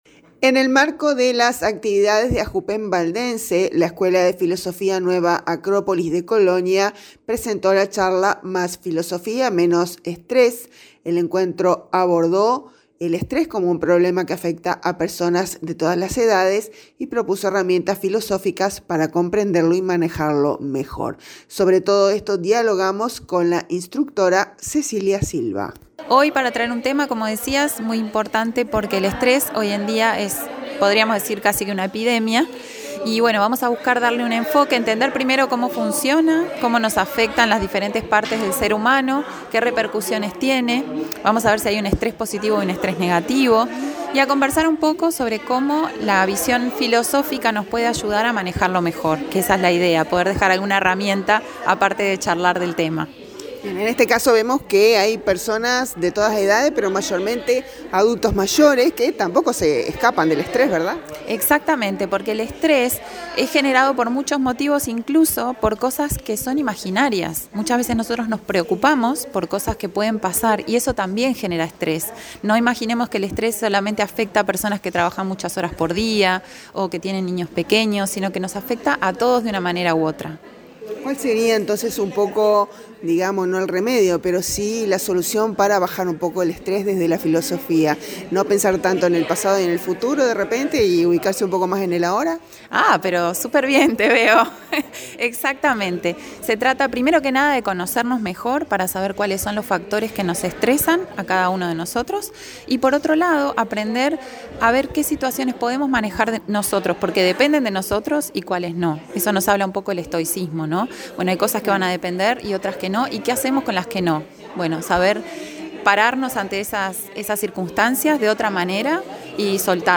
Sobre todo esto dialogamos